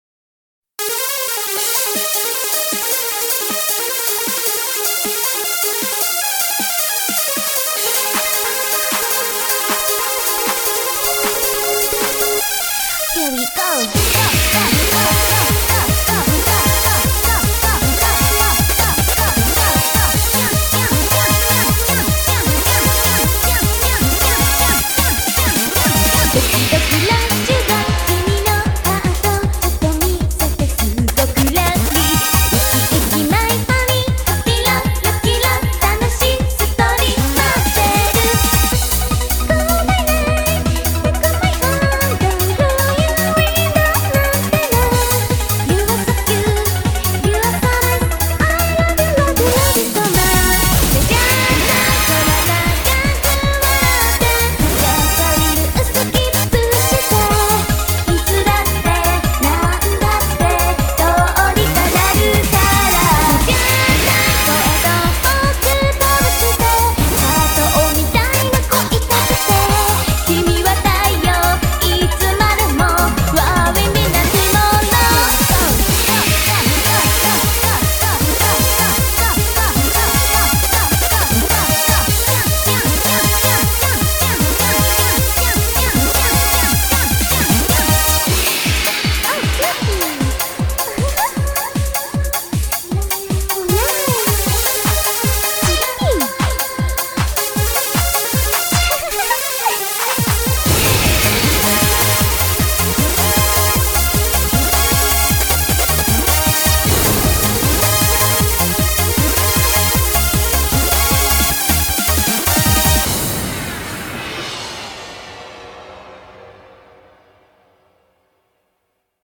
BPM155
EUROBEAT